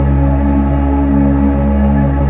PAD JANET0LR.wav